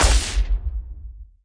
Wep Paperplane Explode Sound Effect
wep-paperplane-explode.mp3